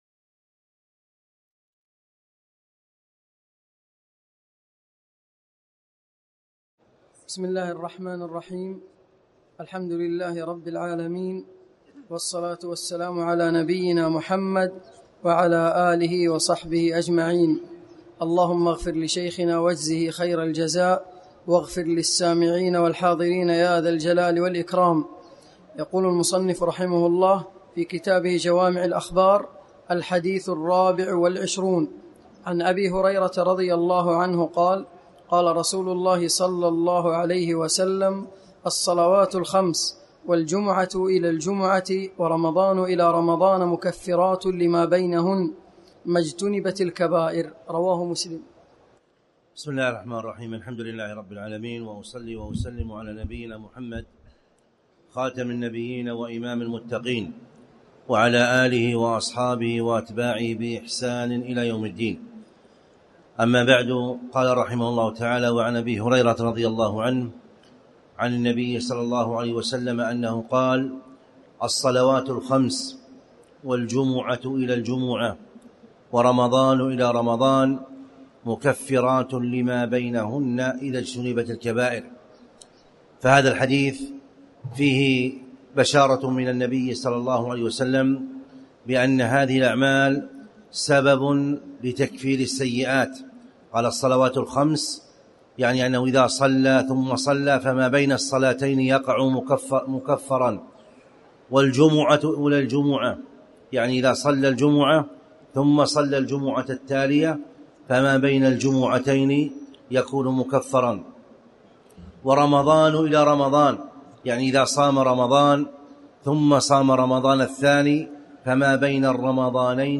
تاريخ النشر ٢٥ رمضان ١٤٣٩ هـ المكان: المسجد الحرام الشيخ